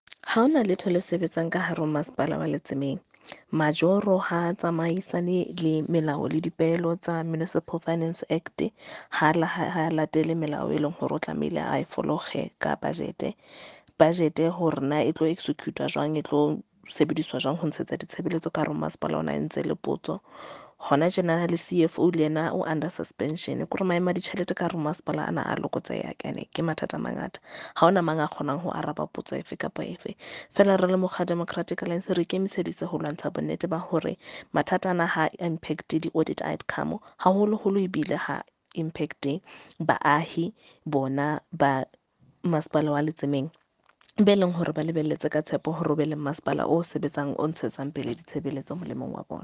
Sesotho by Karabo Khakhau MP.
Sotho-voice-Karabo-18.mp3